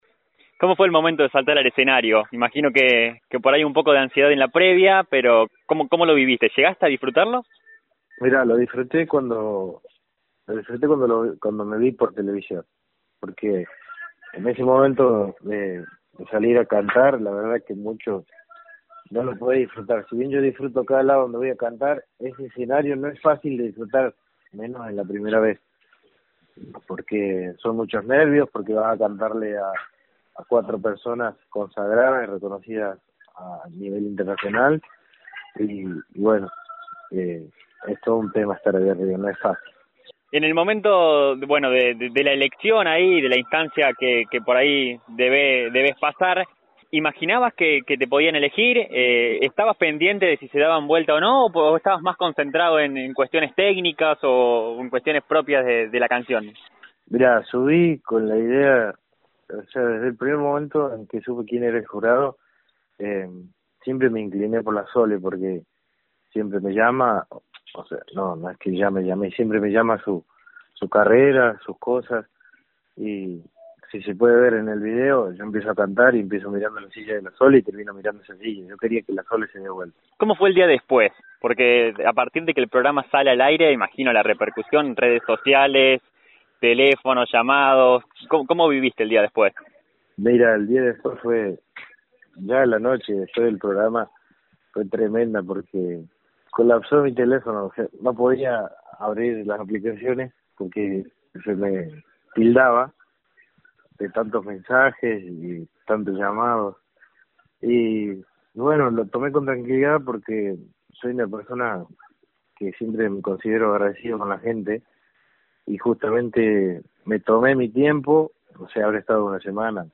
En una entrevista que brindó a FM Samba